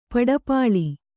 ♪ paḍapāḷi